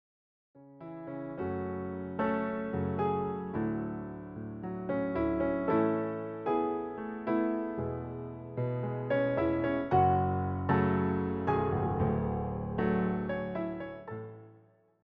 all reimagined as solo piano pieces.
just the piano, no vocals, no band.